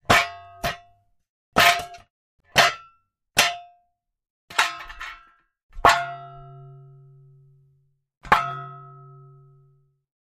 Shovel, Metal, Hits, Various x7